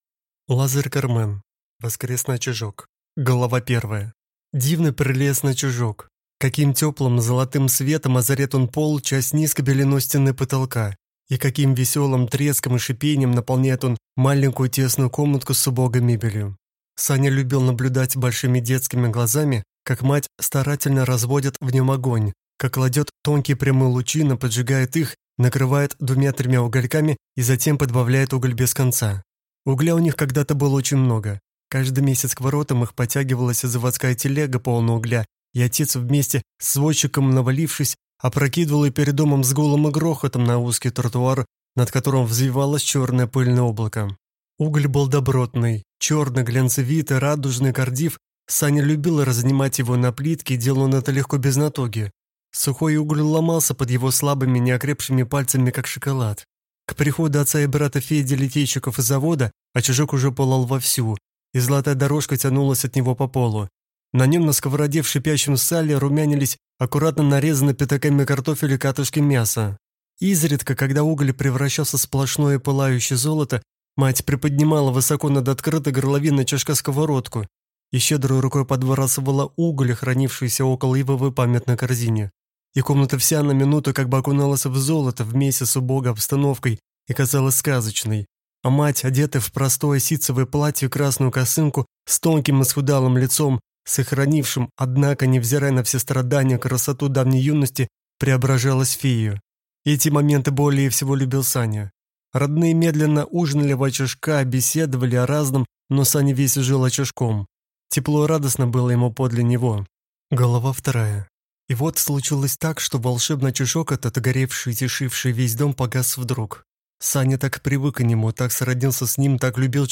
Аудиокнига Воскресный очажок | Библиотека аудиокниг